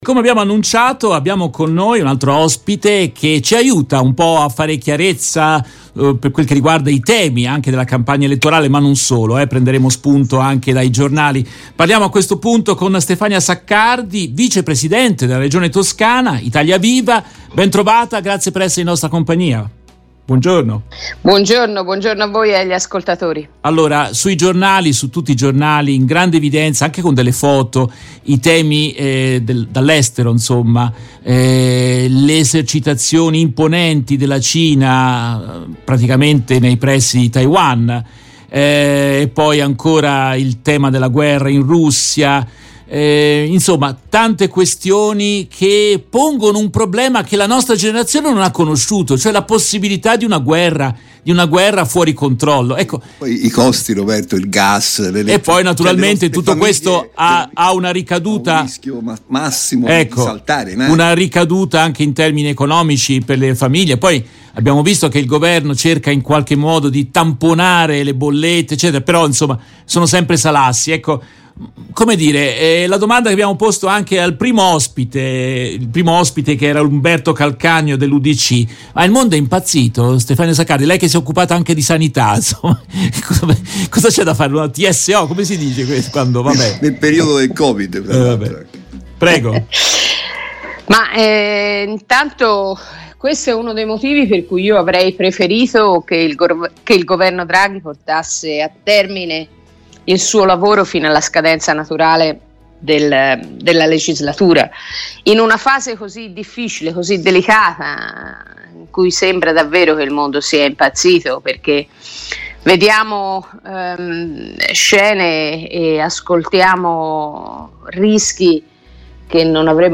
In questa trasmissione intervistano Stefania Saccardi, vicepresidente Regione Toscana ed esponente di Italia Viva.